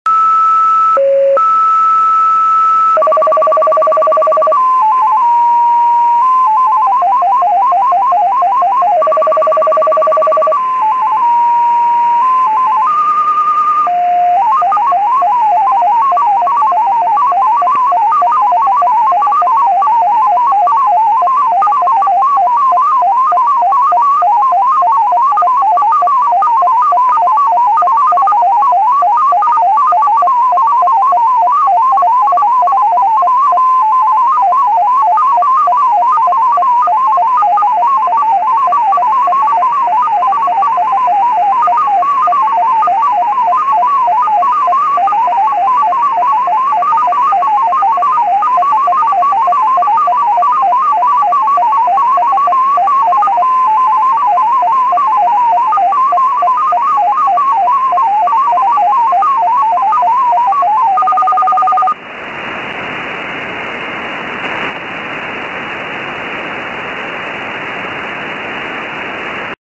First heard in December 2003, the XPA numbers station is a MFSK digital mode station operated by the Russian SVR. Formerly used the 20 baud rate, but now only uses the 10 baud rate (see below for 20 baud sample).
XPA using the 20 Baud Rate
1.) 2 Minutes of high and short pitches lead-in tones
2.) Message sync tones
Emission Mode: USB (Formerly AM)